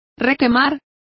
Complete with pronunciation of the translation of overcook.